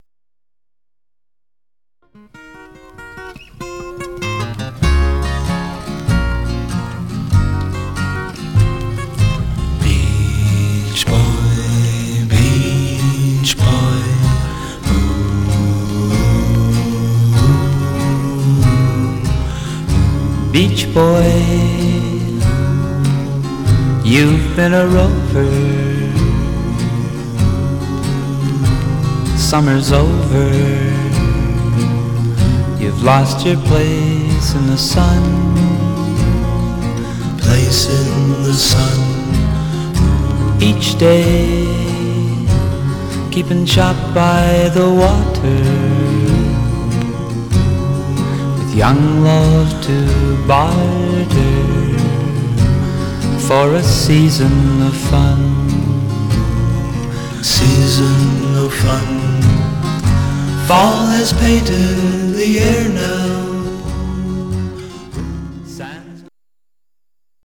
Mono
Teen